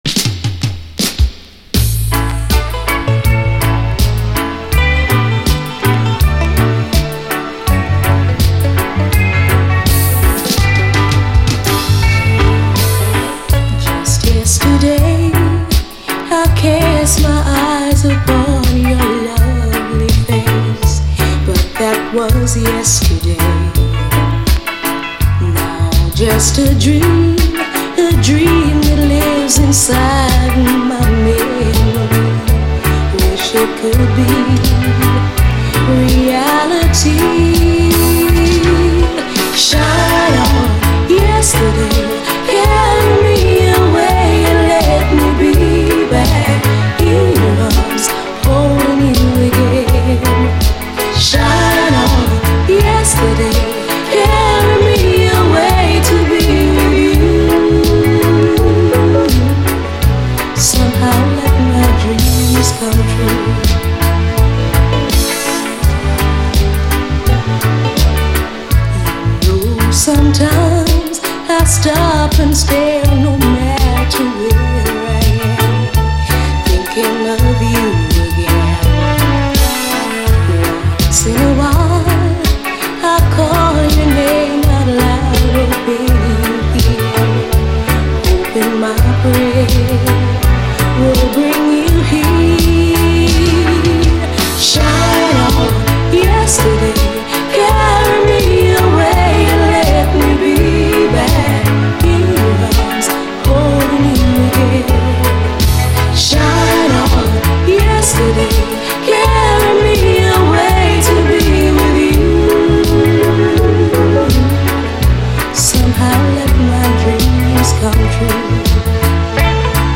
REGGAE
わかりやすい美メロがUKラヴァーズに完璧にマッチ！